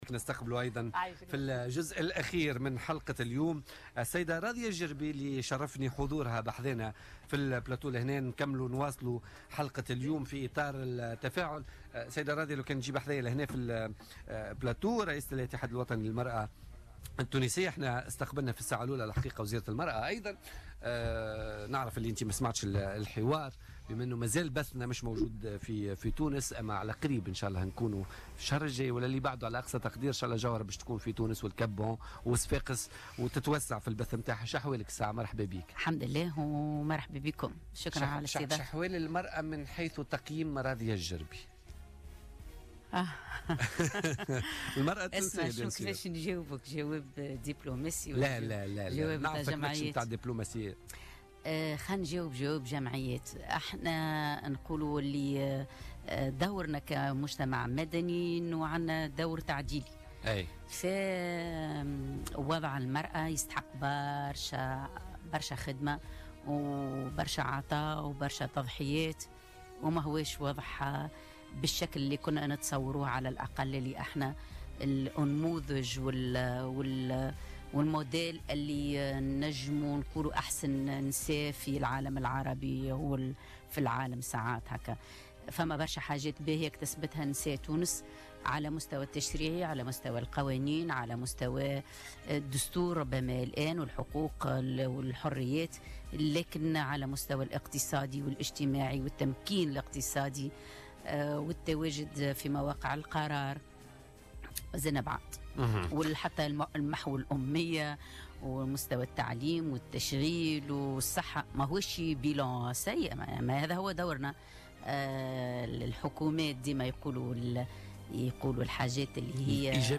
وثمنت ضيفة "بوليتكا" وجود المرأة في المكتب التنفيذي للاتحاد العام التونسي للشغل ضمن القيادة الجديدة.